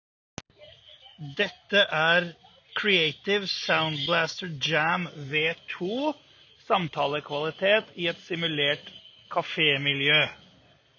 Creative-Sound-Blaster-JAM-V2-samtalelyd-café.m4a